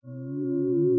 dash_charge.wav